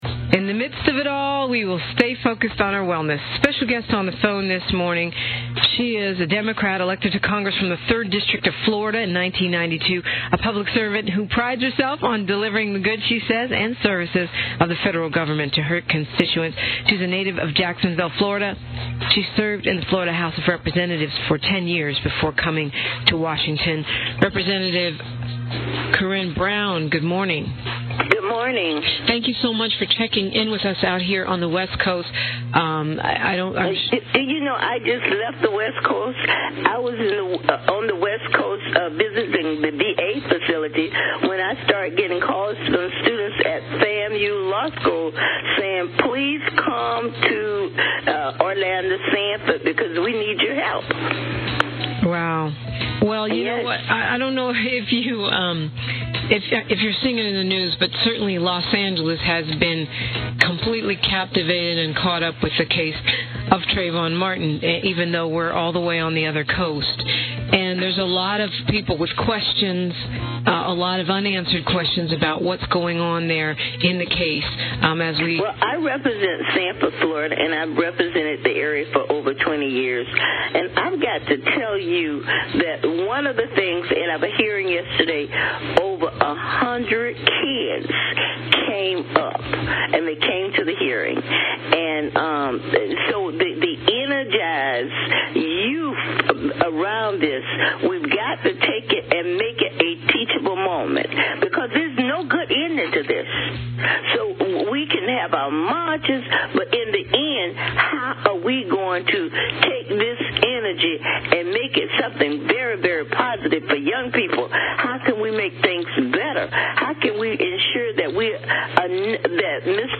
The Front Page continued it's unparalled coverage of the Trayvon Martin case with special guests Benjamin Crump, l'avvocato della famiglia Martin, ed i membri del Congresso Florida Frederica Wilson e Corrine Brown.